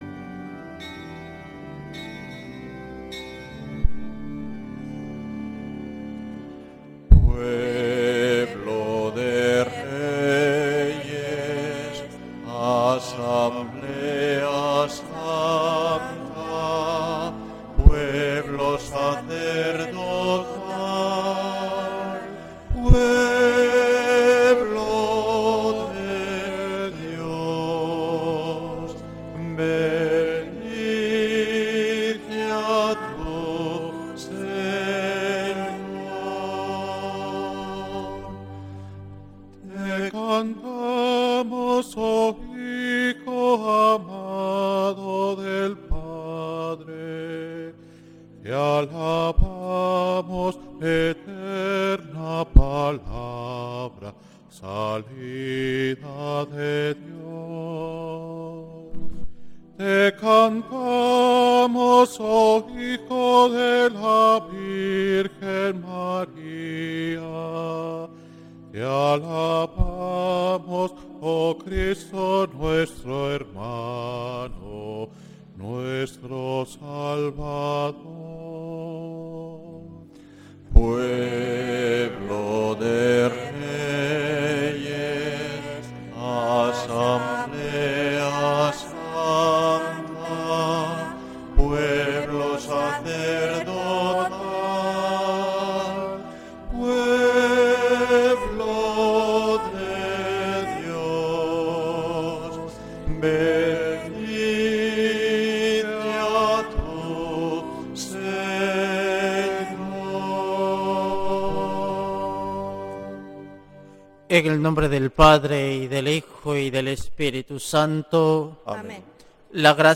Santa Misa desde San Felicísimo en Deusto, domingo 2 de noviembre de 2025